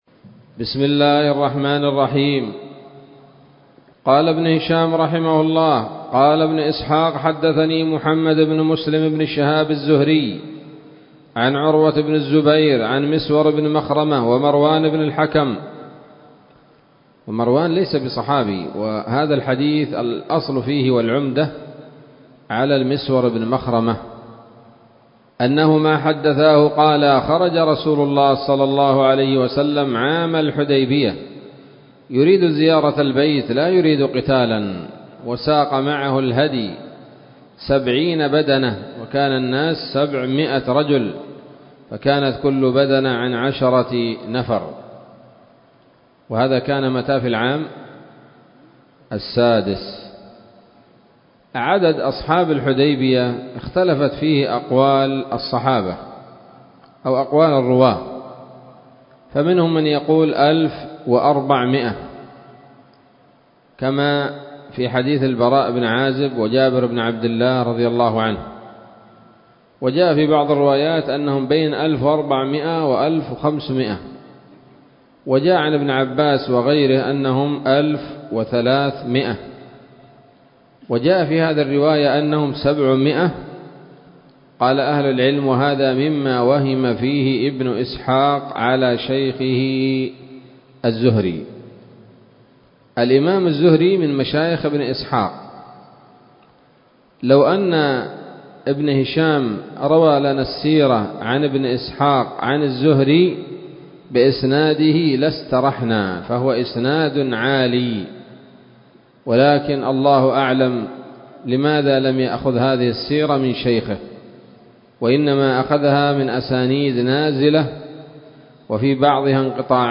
الدرس التاسع والعشرون بعد المائتين من التعليق على كتاب السيرة النبوية لابن هشام